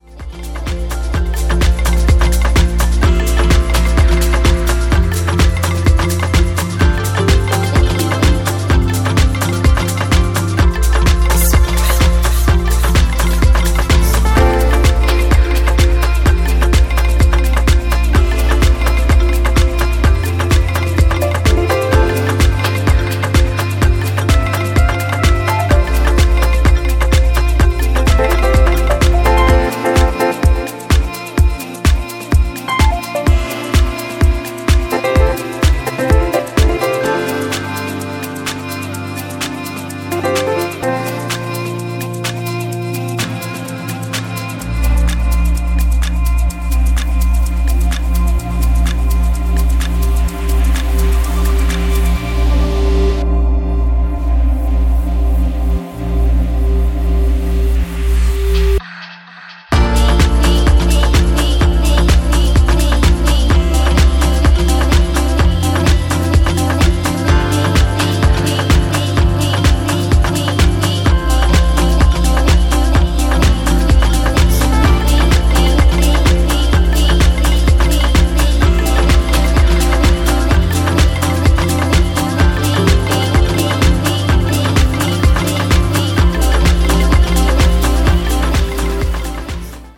アップリフトなブレイクビーツを導入しながらサブベースも厚く鳴らし